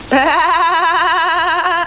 leeloolaugh.mp3